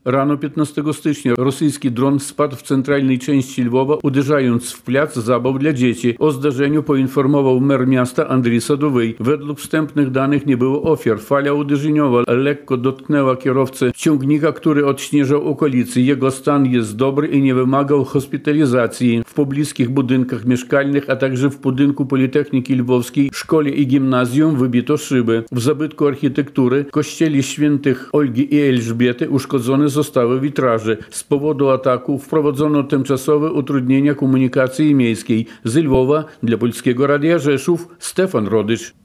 Ze Lwowa dla Polskiego Radia Rzeszów